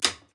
btn_click_05.wav.mp3